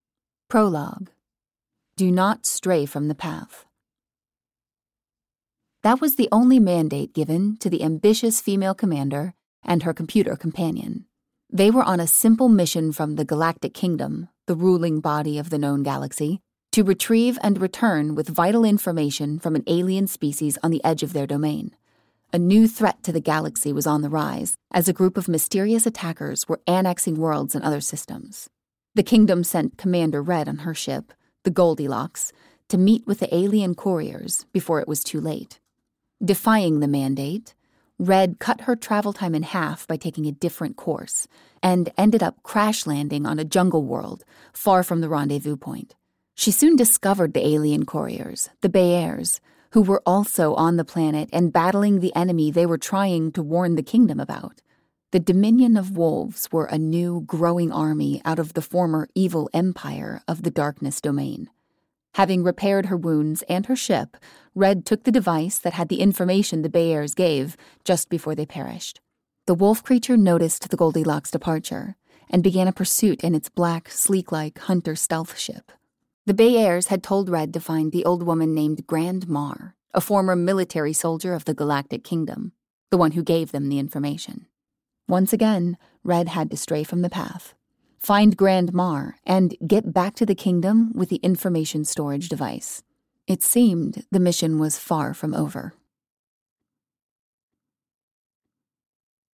Outer Red: Part Two (EN) audiokniha
Ukázka z knihy